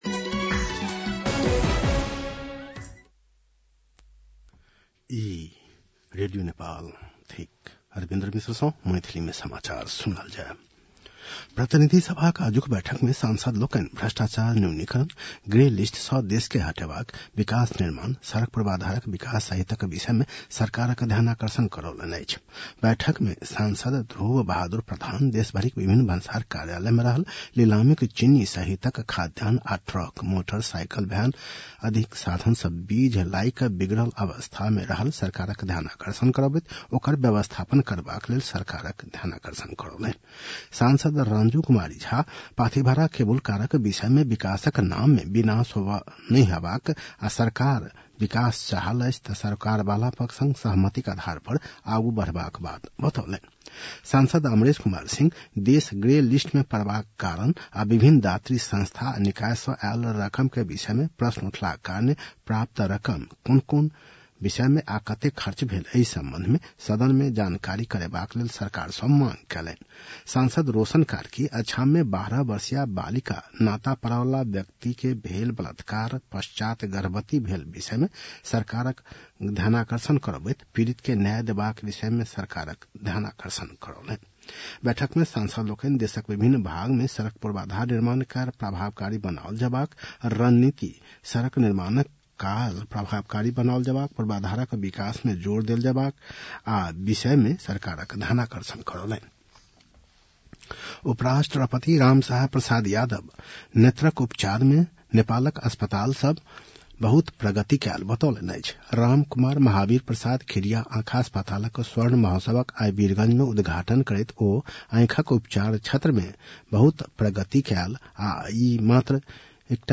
मैथिली भाषामा समाचार : १३ फागुन , २०८१
Maithali-news-11-12.mp3